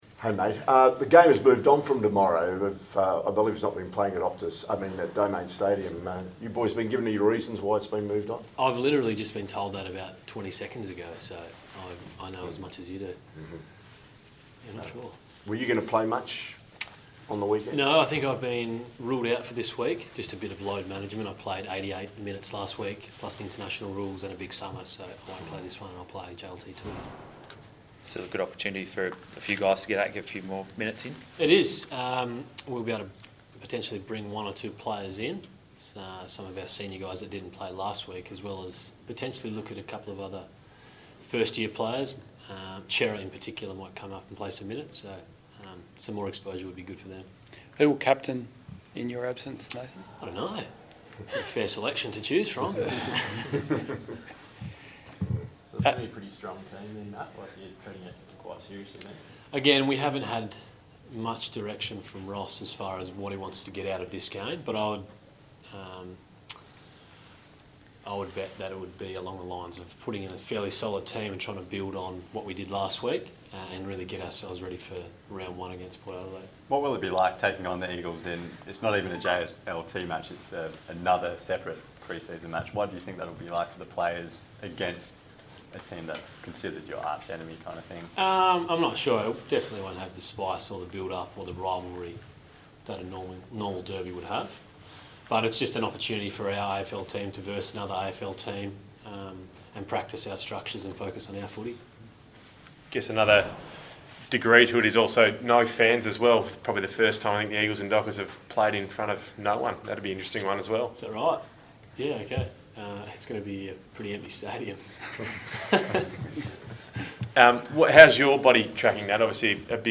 Nat Fyfe chats to the media after Fremantle announced their 2018 leadership group.